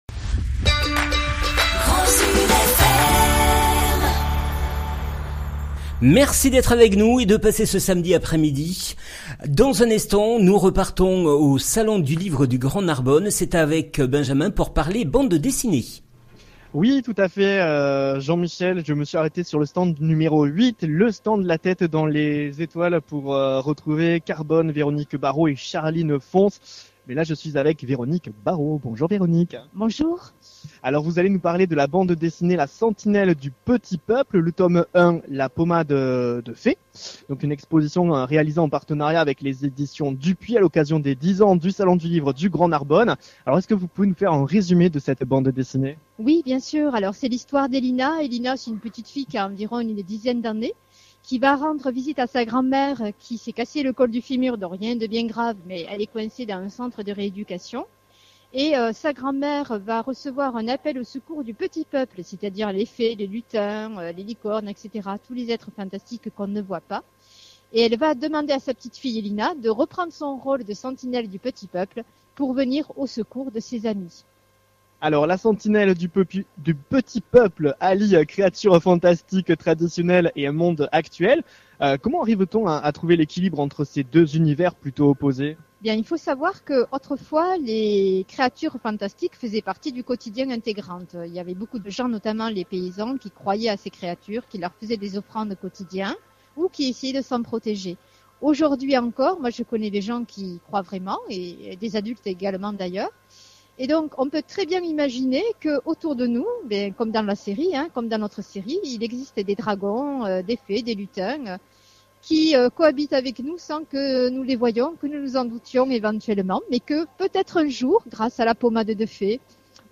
En direct du Salon du livre du Gd Narbonne